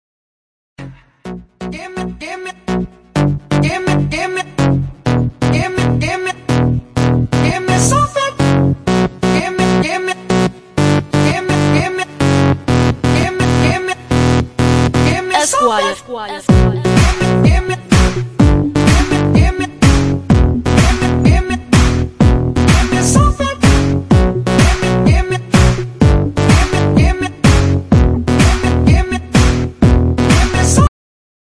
громкие
dance
EDM
нарастающие
басы
house
заедающие